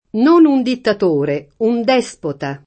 despota [d$Spota] s. m.; pl. -ti — possibile, se riferito a donna, un uso come s. f. (col pl. -te) — es. con acc. scr.: non un dittatore, un dèspota [